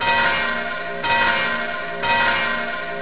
click church to ring bell
bellring.wav